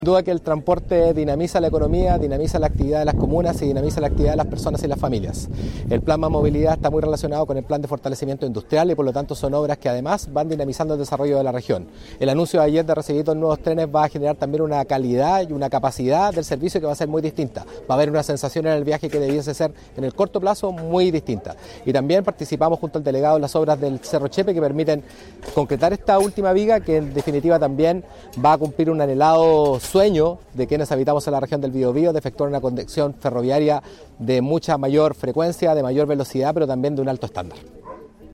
El inicio de obras contó con la presencia de autoridades regionales, quienes concordaron en destacar la importancia de la iniciativa que busca mejorar la conectividad urbana de la comuna, así como también potenciar la seguridad vial en el entorno de la nueva infraestructura.
En tanto, el seremi de Transportes de la región del Biobío, Patricio Fierro, reforzó la importancia de generar estas obras, ya que el transporte impacta positivamente en la calidad de vida de las personas.